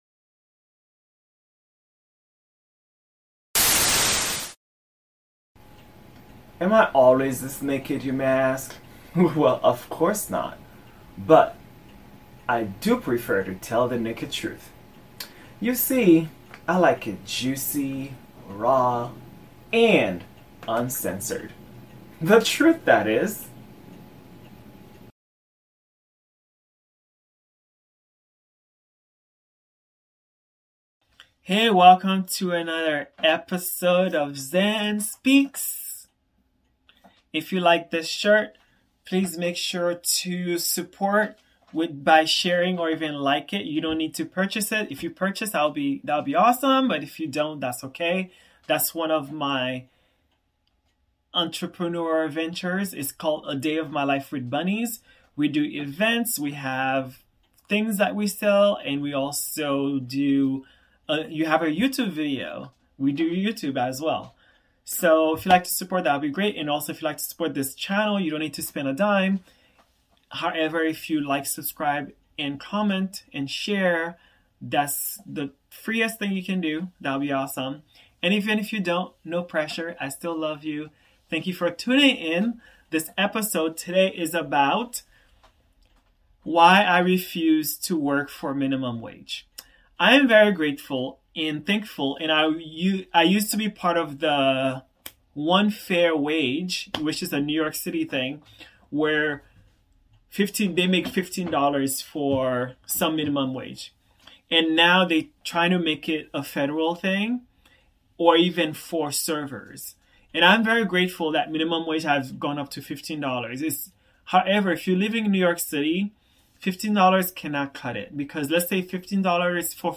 Solo.